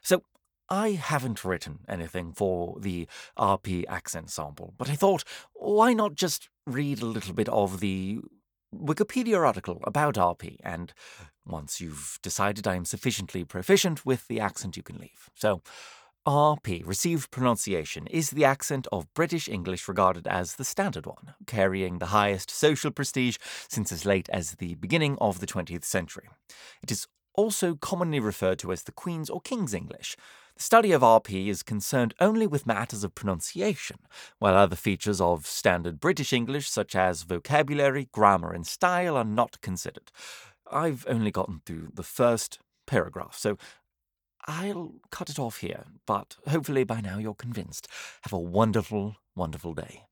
british rp | natural
RP.mp3